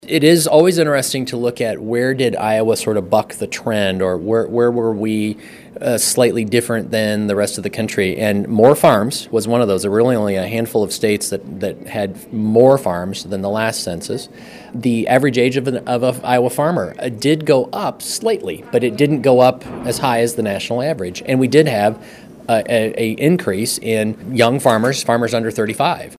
Agriculture Secretary Mike Naig says Iowa had more farms and younger farmers in 2022 than the previous census.